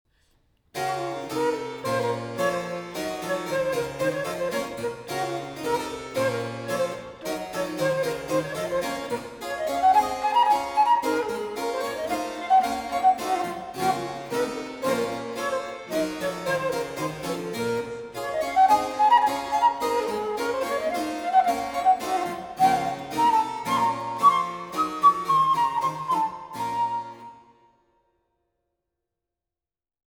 Pompeusement